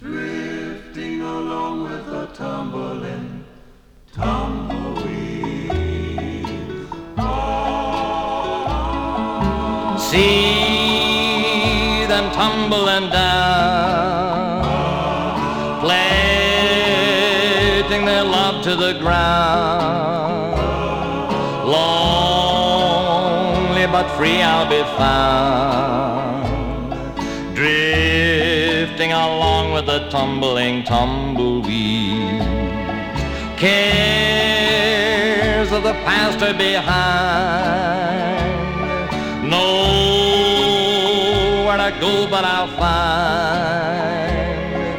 Country, Rock, World　USA　12inchレコード　33rpm　Stereo